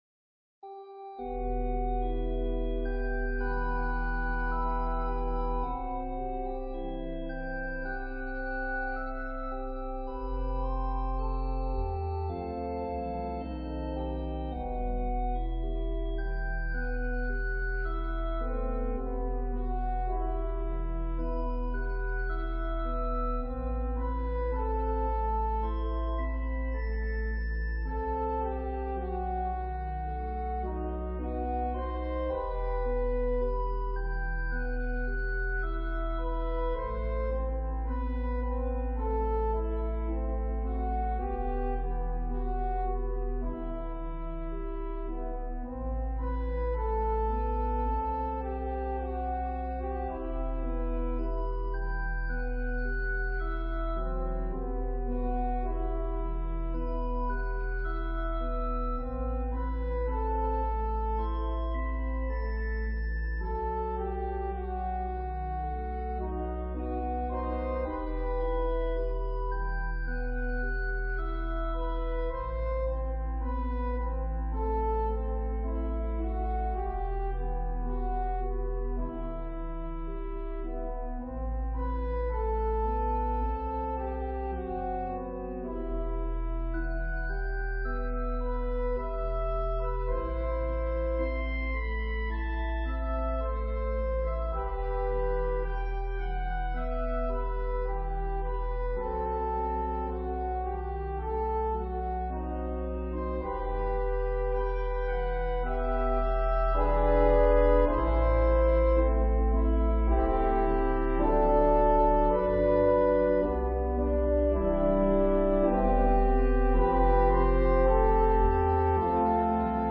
An organ solo arrangement
Voicing/Instrumentation: Organ/Organ Accompaniment